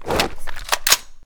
CosmicRageSounds / ogg / general / combat / weapons / default_shootable / draw1.ogg